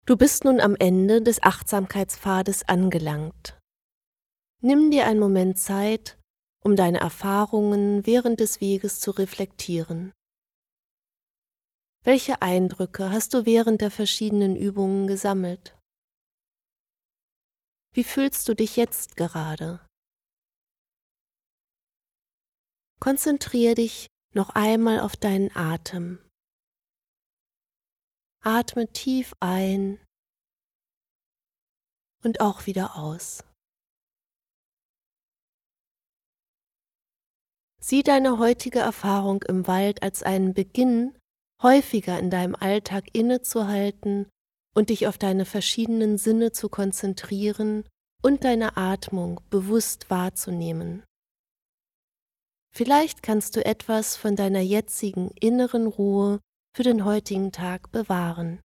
08_Outro__weiblich_.mp3